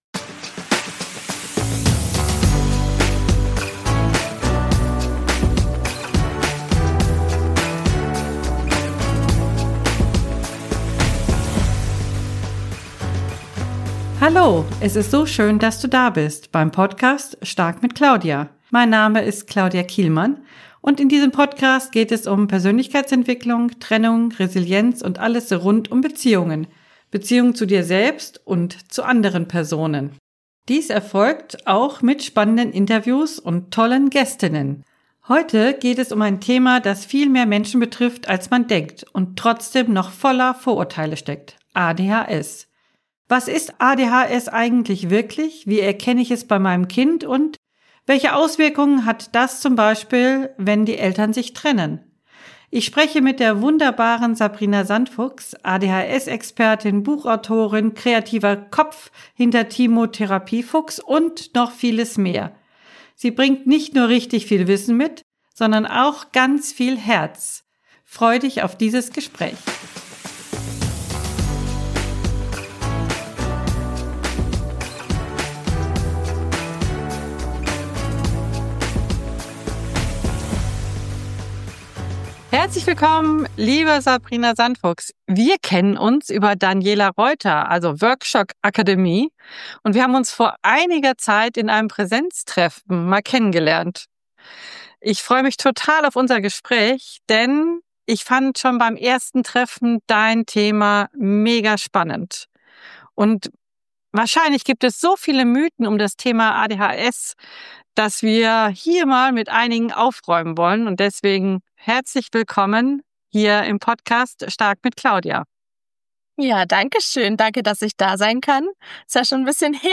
#047 ADHS - Was genau ist dies eigentlich? – Interview